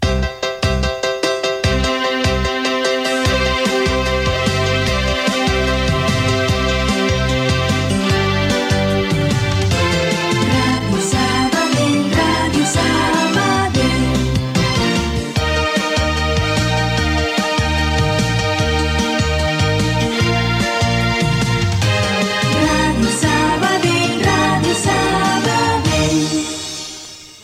Indicatiu cantat de l'emissora